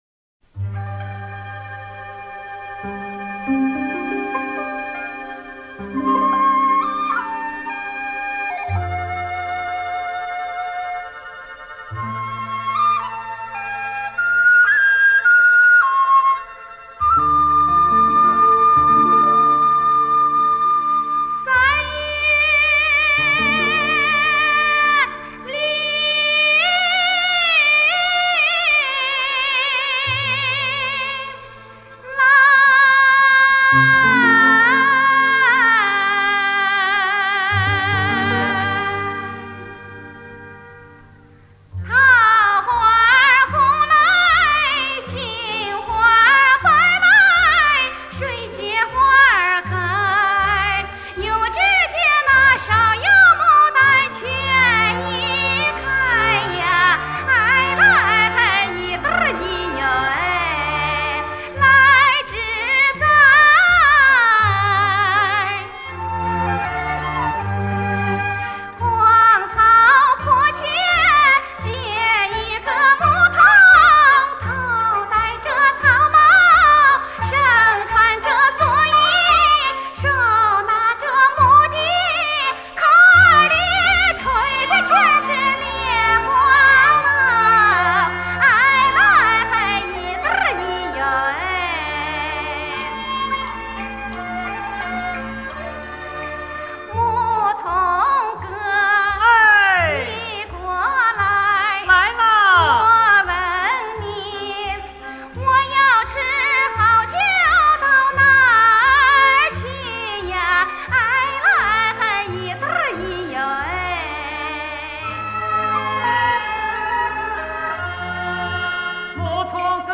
录音听上去是在演出场地录制的，但不能肯定是演唱会录音（没有发现环境自然噪音）。
安徽民歌
二位歌者的方言还是挺重的。